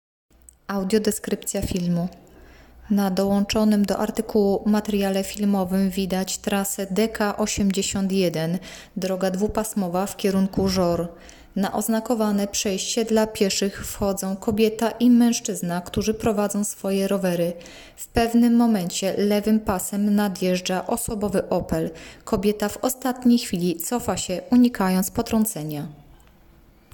Nagranie audio Audiodeskrypcja do filmu